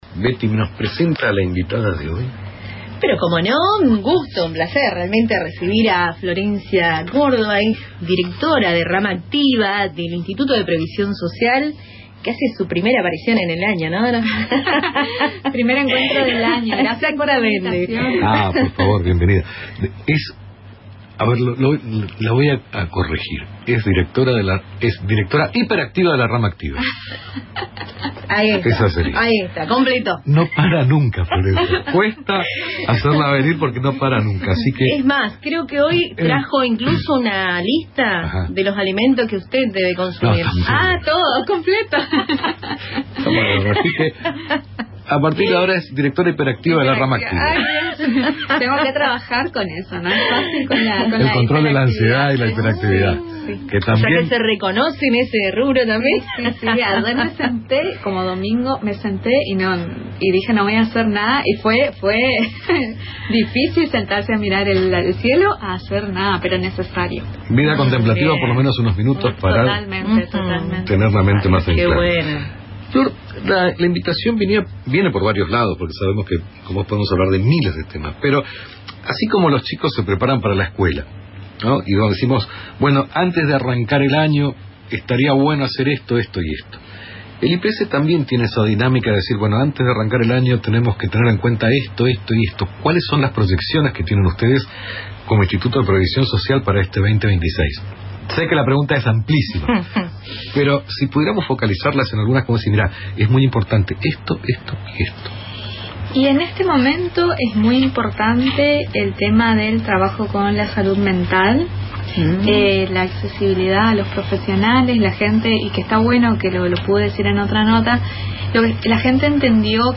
En diálogo con el programa Lo que faltaba, que se emite por Radio Tupambaé